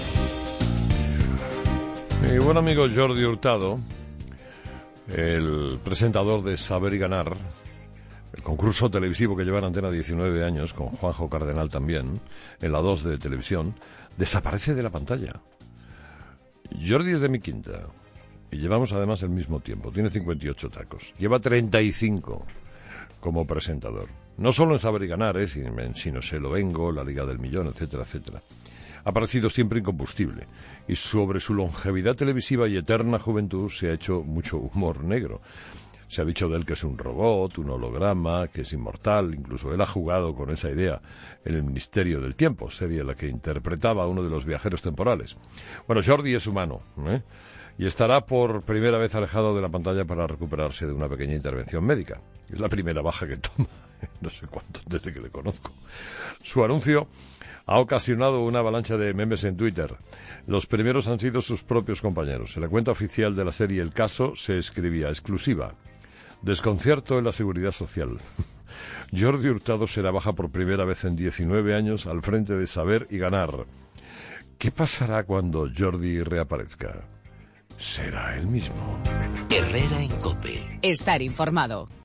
Carlos Herrera habla de su amigo Jordi Hurtado, que desaparece de la pantalla por una operación